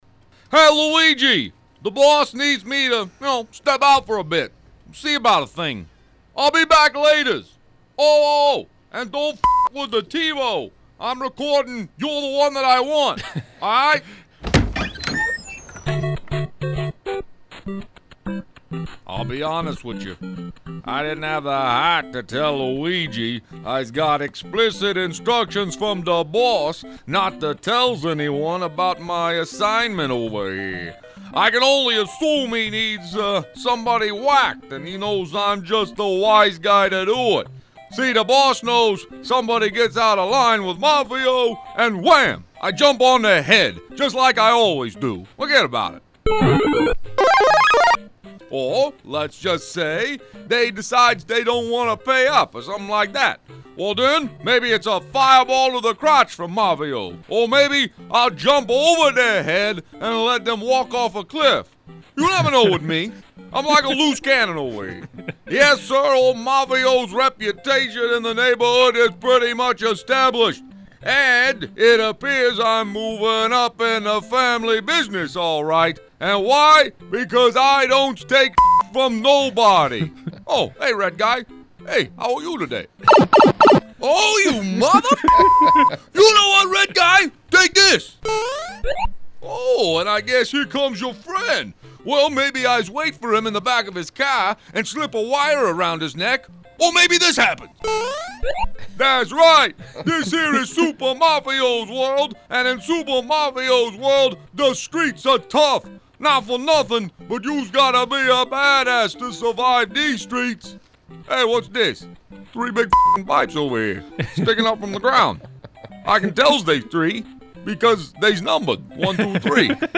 The infectious laugh in the background